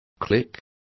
Complete with pronunciation of the translation of cliché.